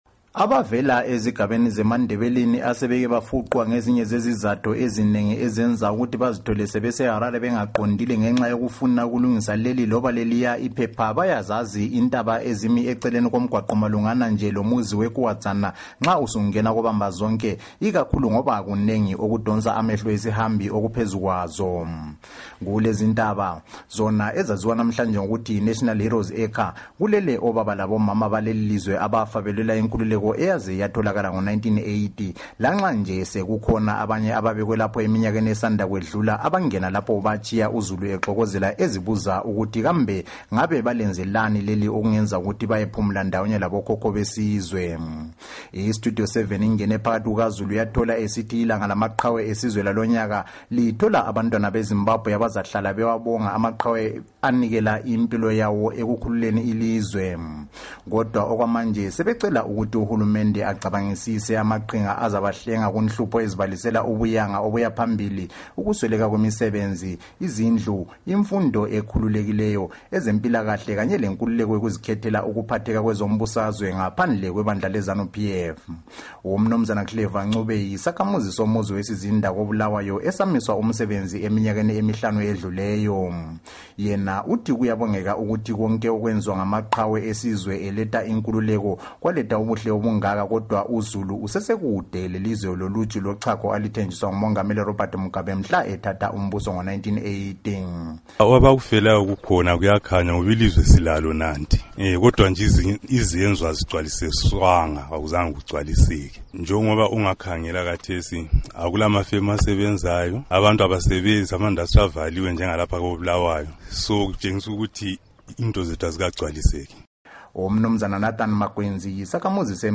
Uyalandisa